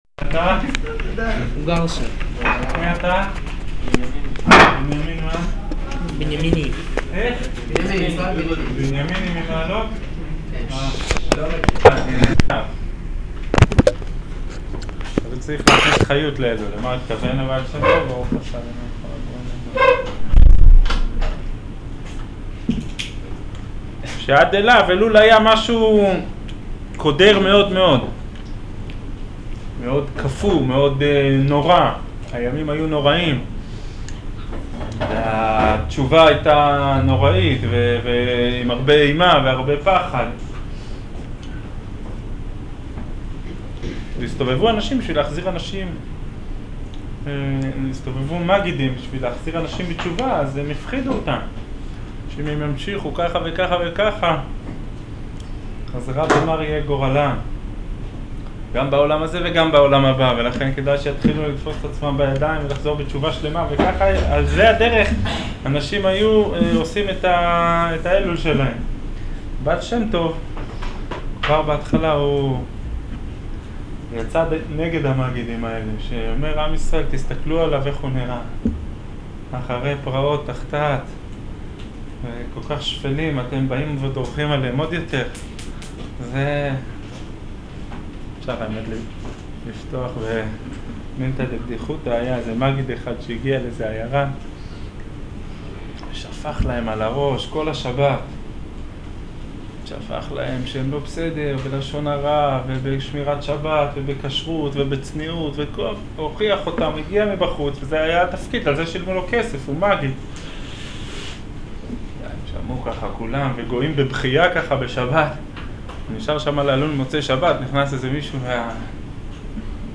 שיעור אגרת התשובה